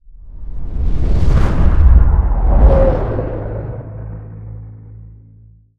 cinematic_deep_bass_pass_whoosh_03.wav